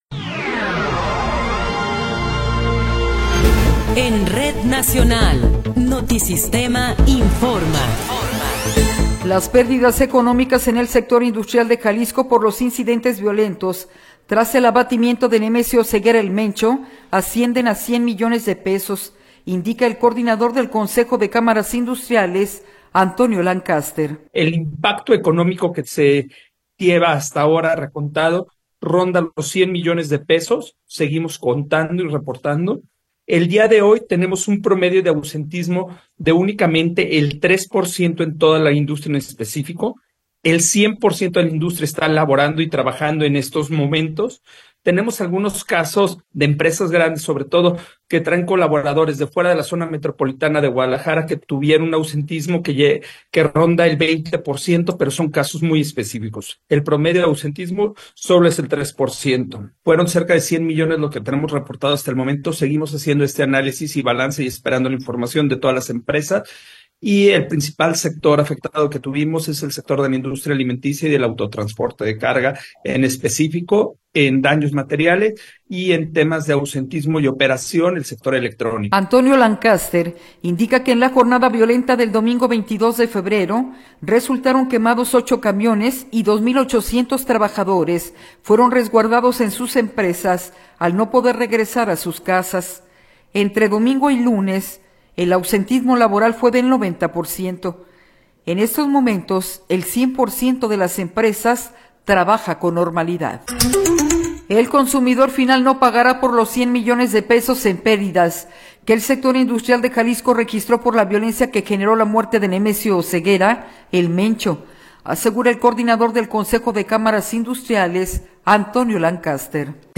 Noticiero 15 hrs. – 1 de Marzo de 2026
Resumen informativo Notisistema, la mejor y más completa información cada hora en la hora.